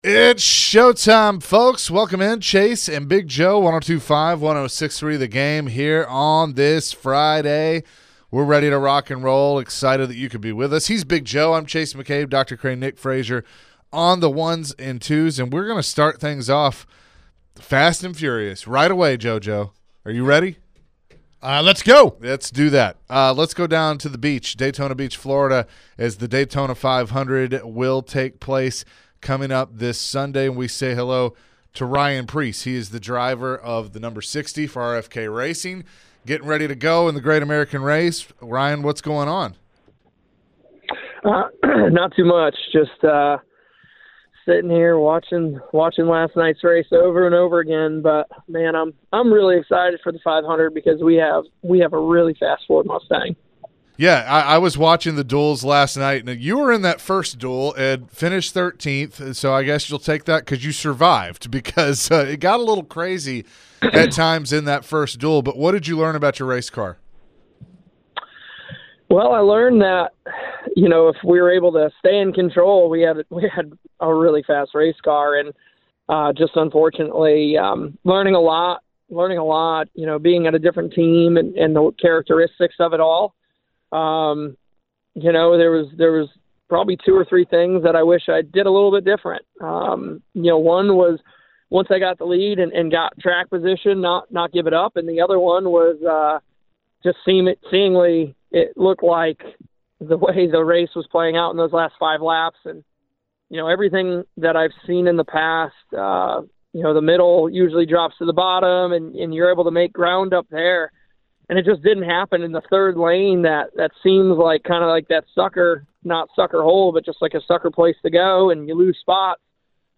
the guys chat with NASCAR Driver Ryan Preece ahead of the Daytona 500! Ryan answered a few questions regarding the upcoming race and how his team can succeed with a win.